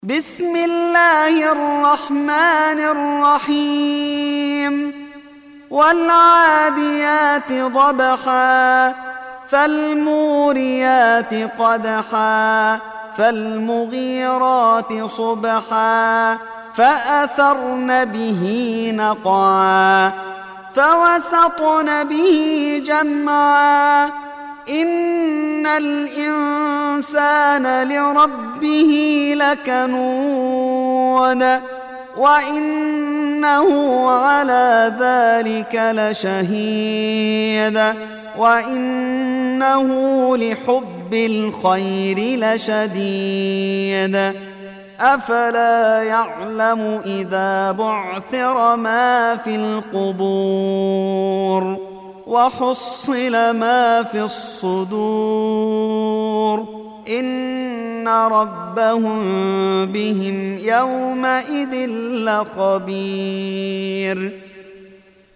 Surah Sequence تتابع السورة Download Surah حمّل السورة Reciting Murattalah Audio for 100. Surah Al-'Adiy�t سورة العاديات N.B *Surah Includes Al-Basmalah Reciters Sequents تتابع التلاوات Reciters Repeats تكرار التلاوات